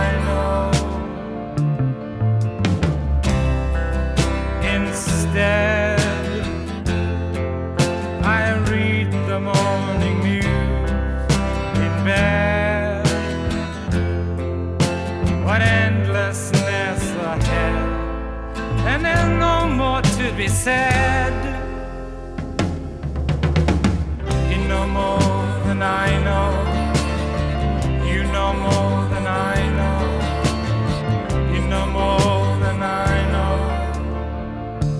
Жанр: Поп музыка / Рок / Альтернатива / Фолк / Классика